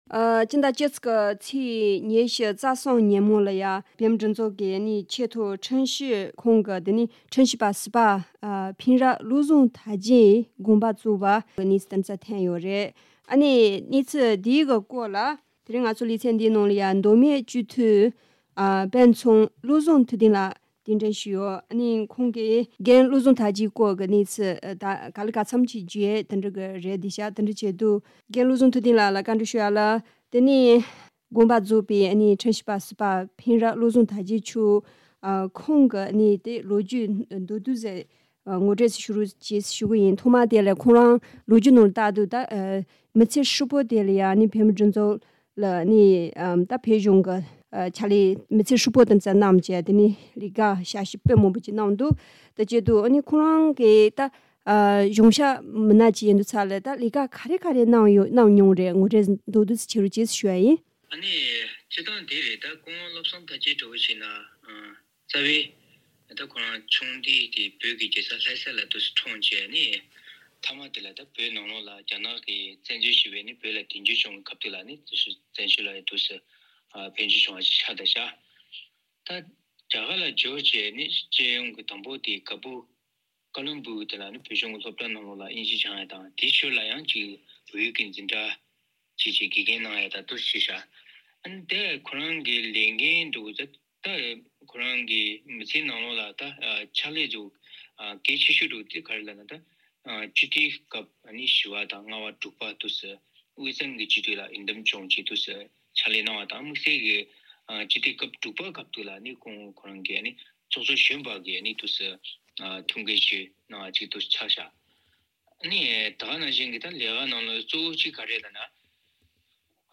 བཅར་འདྲི་ཞུས་པ